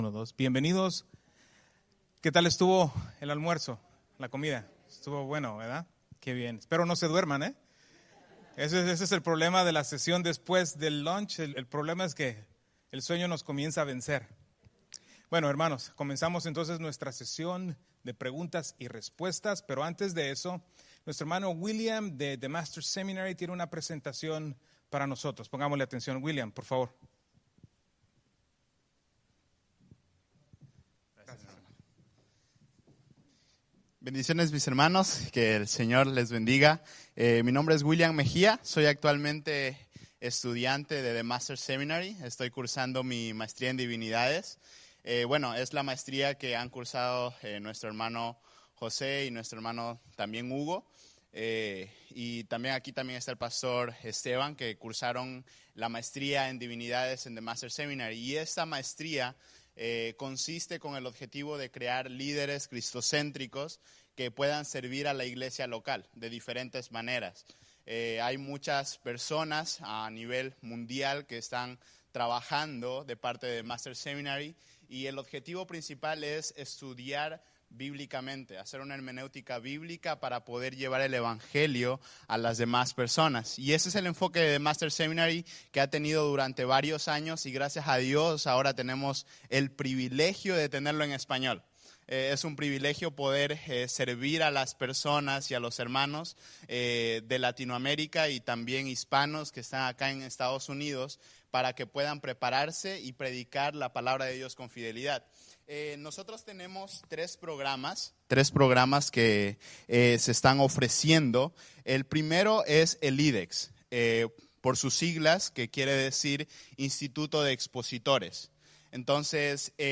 Preguntas y Respuestas (Conferencia 2023)
Conferencia Biblical de la Gracia 2023 Preguntas y Respuestas